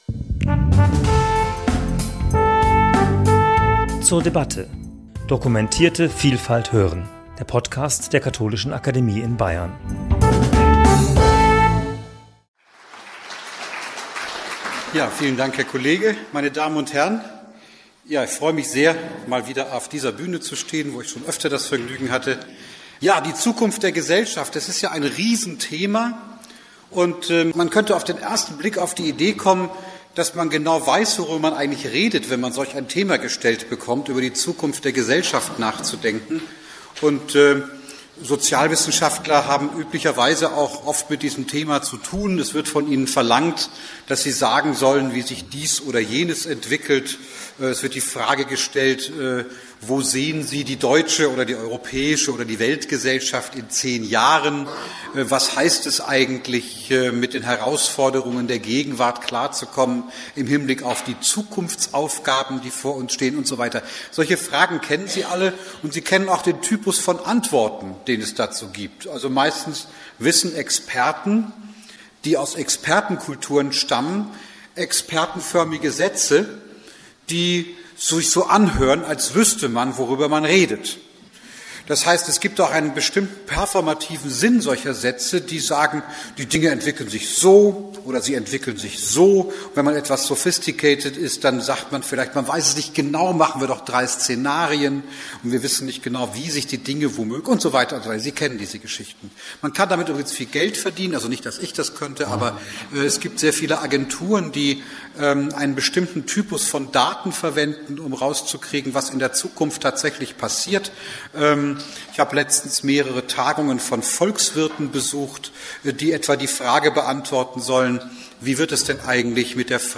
Nachmittags referierte der Soziologe Armin Nassehi über die Gesellschaft, deren Zukunft sehr unterschiedlich sein kann.